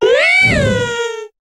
Cri d'Hélionceau dans Pokémon HOME.